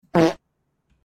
Fart Sound Effect Free Download
Fart